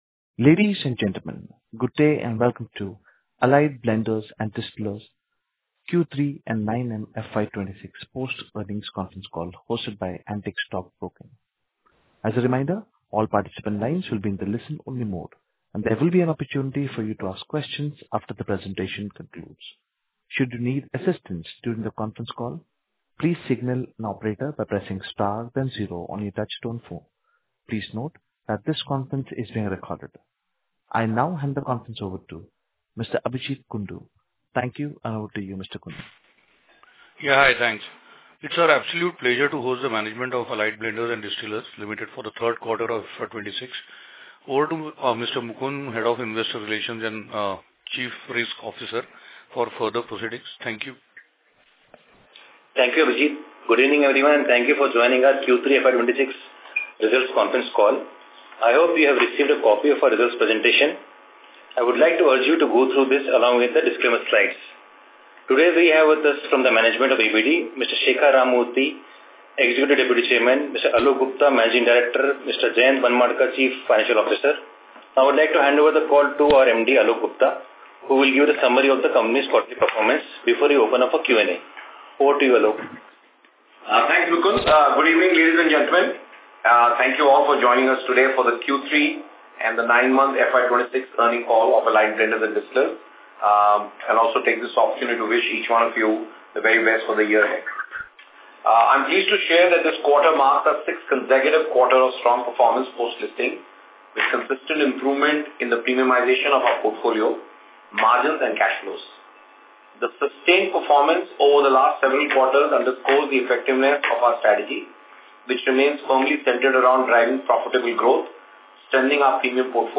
Earnings-Call-3QFY26.mp3